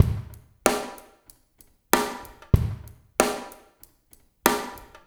AFS DRUMS -L.wav